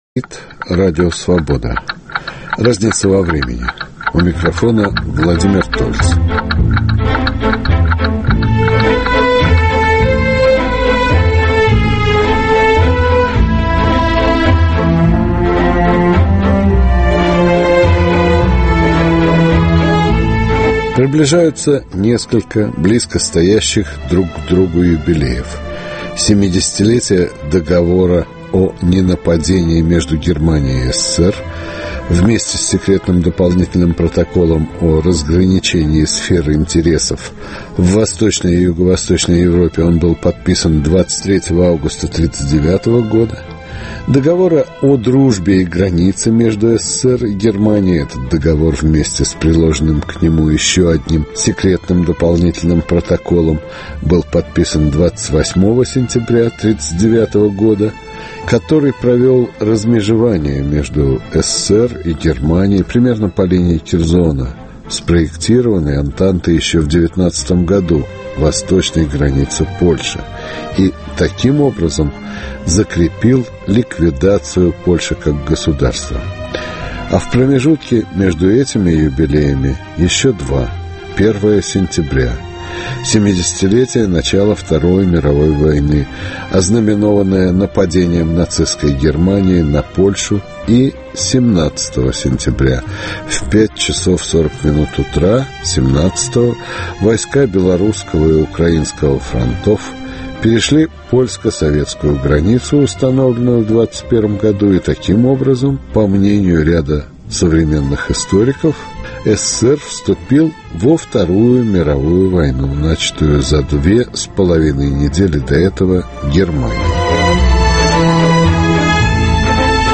Речь пойдет о недавно опубликованном сборнике документов из Архива Президента РФ и прелюдии к событиям 1939 г. Гости программы историки